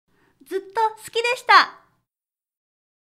ボイス
挨拶高音